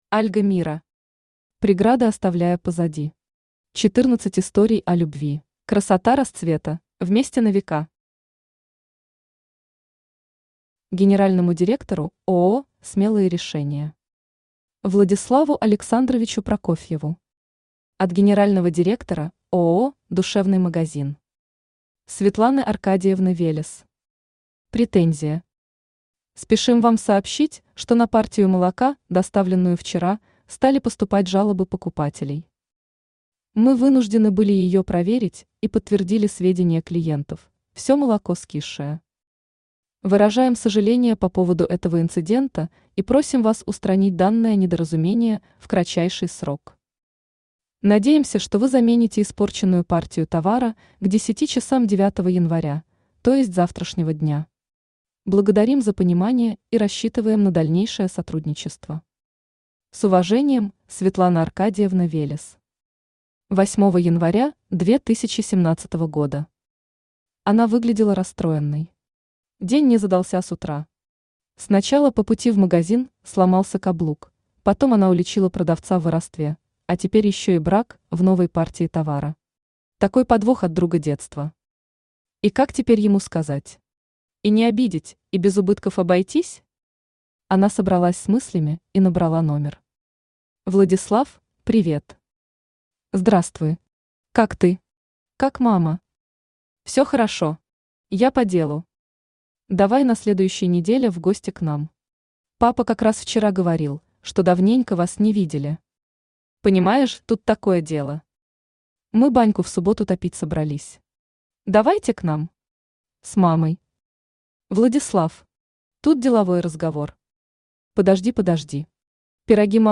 Аудиокнига Преграды оставляя позади. 14 историй о Любви | Библиотека аудиокниг